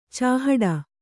♪ cāhaḍa